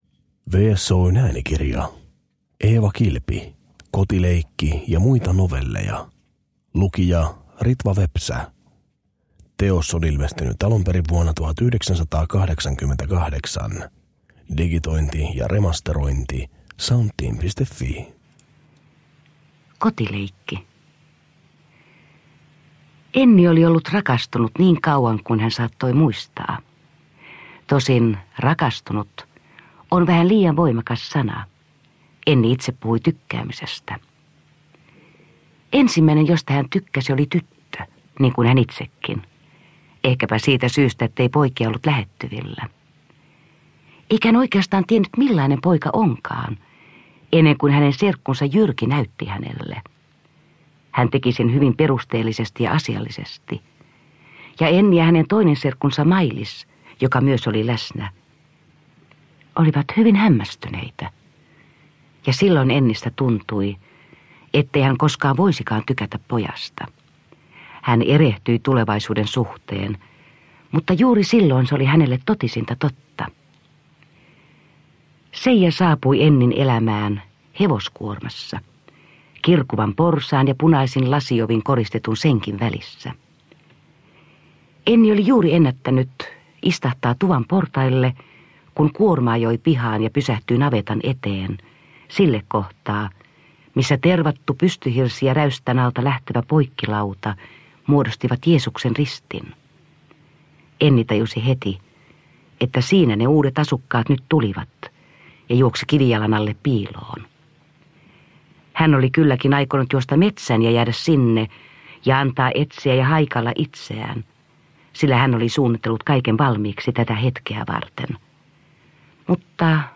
Tulkitsijana on Jussi-palkittu näyttelijä Ritva Vepsä (1941–2016), joka tunnetaan muun muassa Maunu Kurkvaaran ja Jörn Donnerin elokuvista.
Vuonna 1988 C-kasetilla julkaistut Eeva Kilven novellit ovat saaneet uuden elämän digitoituna äänikirjana.
Uppläsare: Ritva Vepsä